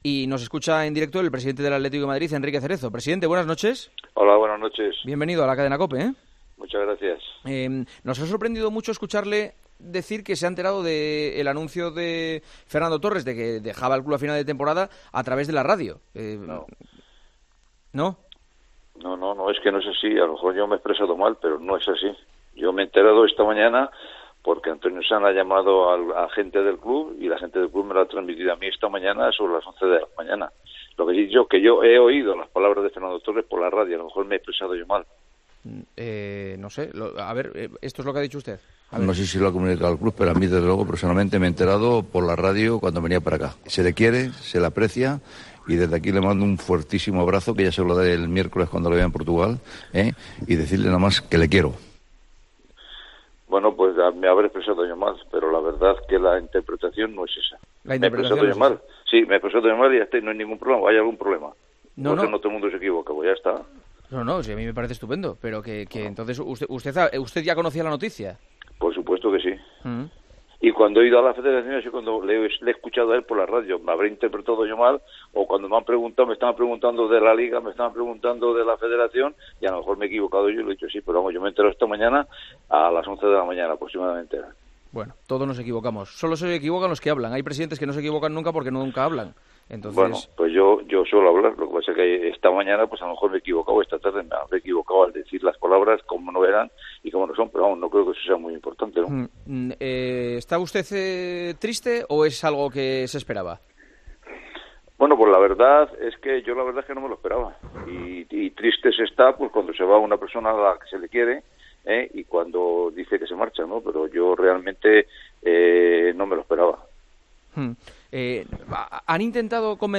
Entrevista en El Partidazo de COPE
El día que Fernando Torres anunció públicamente su marcha del Atlético de MadridEl Partidazo de COPE llamó al presidente del club rojiblanco para conocer su reacción a la noticia.